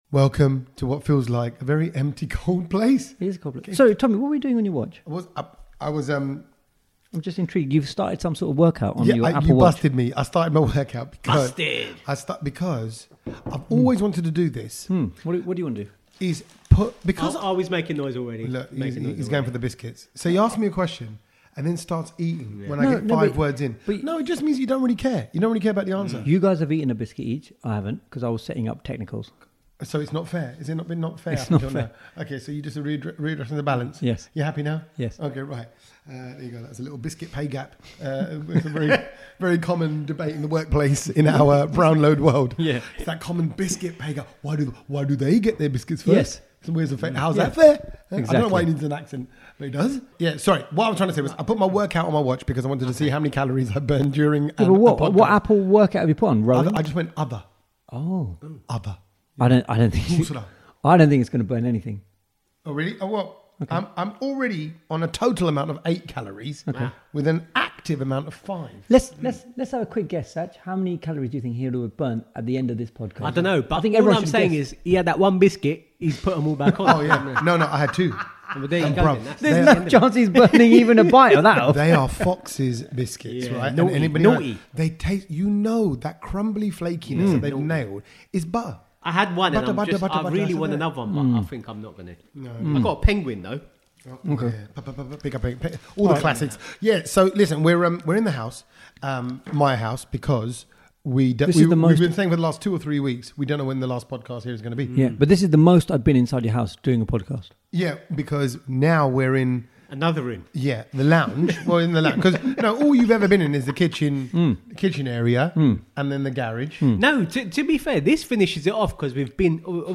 Brownload (that’s an echo!) We are in my empty, hollow feeling house (half way through moving) and reminiscing about last weeks live recording at the London Podcast Festival!